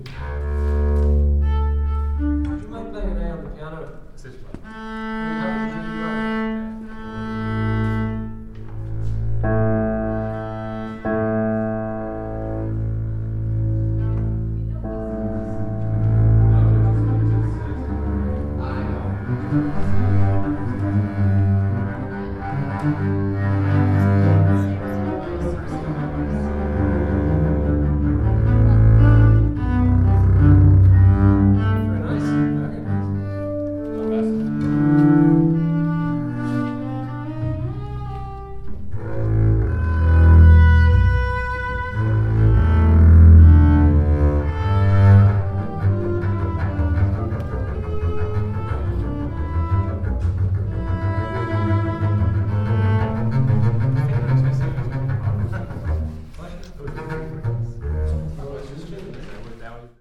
The Irish Chamber Orchestra were kind enough to let me record their tuning up.
Then I got stuck under the grand piano for several movements of a Tchaikovsky piece. Best seat in the house.